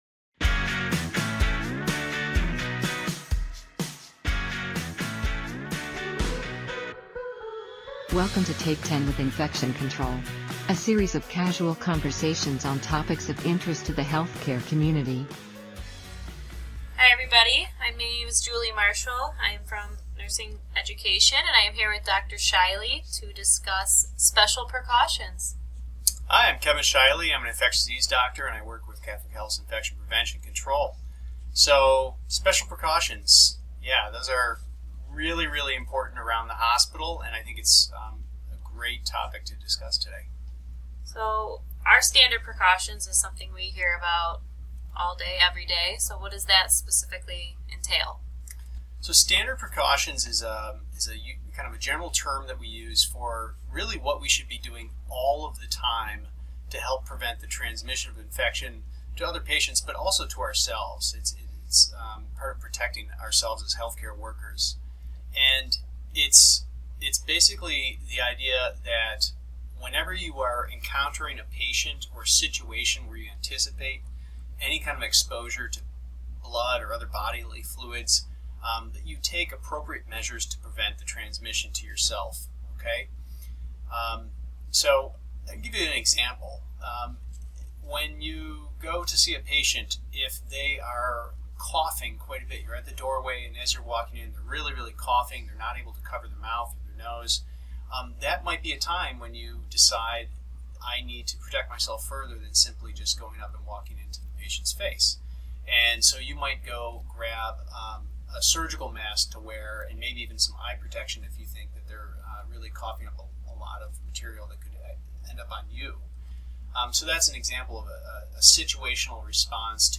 A series of casual conversations